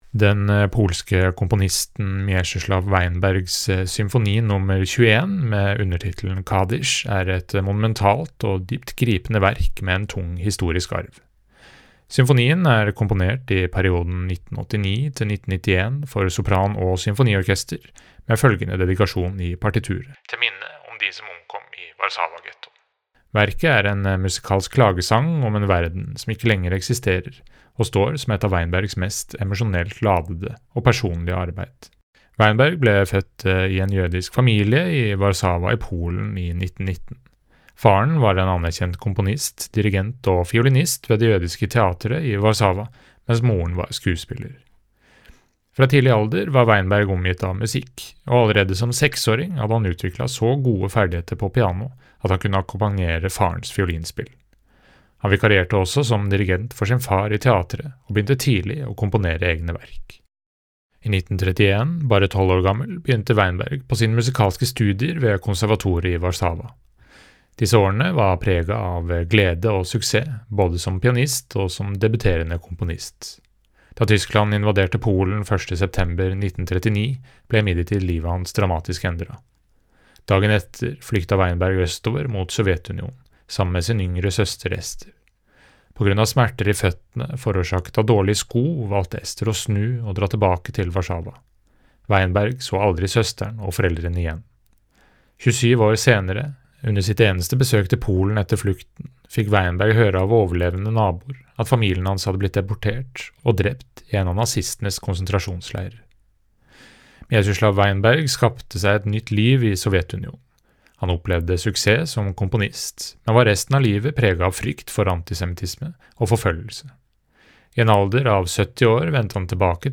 Verkomtale-Mieczyslaw-Weinbergs-Symfoni-nr.-21.mp3